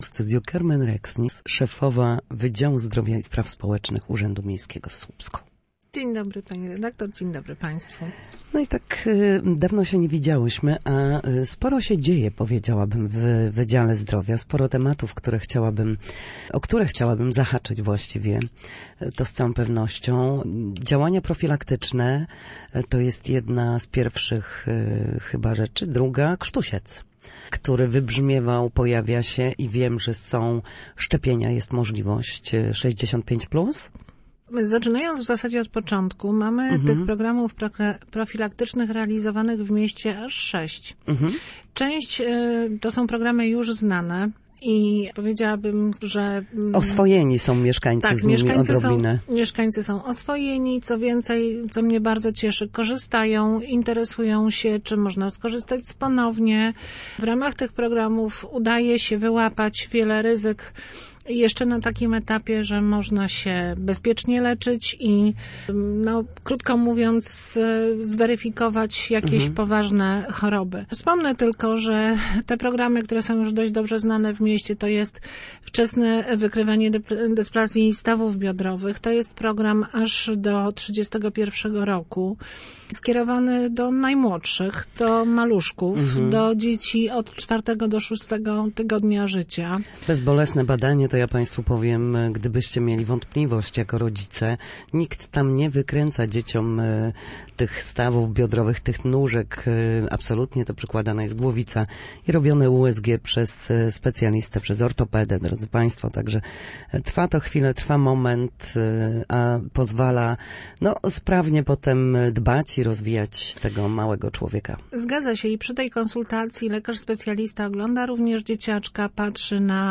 Na naszej antenie mówiła o programach profilaktycznych dla Słupszczan.